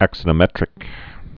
(ăksə-nō-mĕtrĭk)